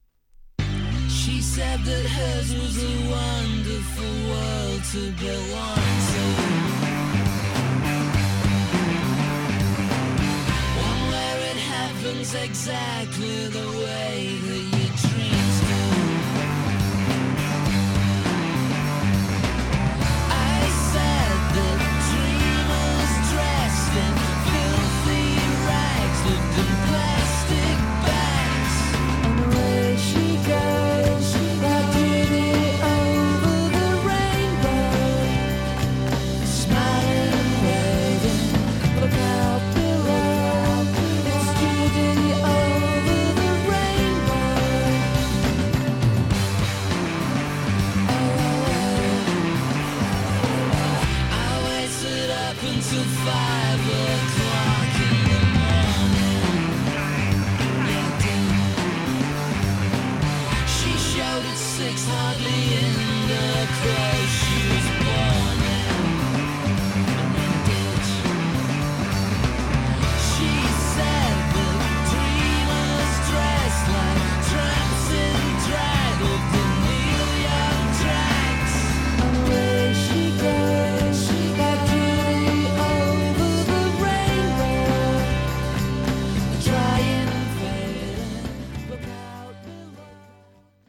タイトル曲はエッジの効いたGtr.にサビで胸キュンさせられるメロが最高なギタポクラブヒットチューン♪
モッドな疾走系